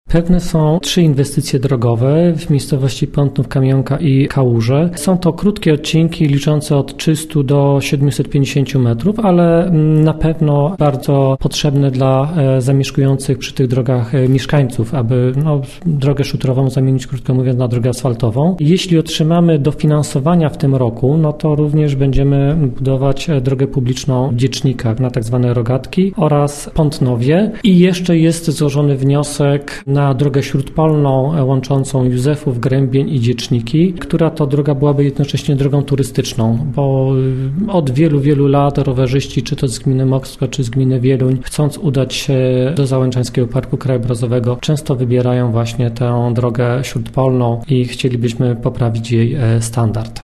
– mówił wójt gminy Pątnów, Jacek Olczyk.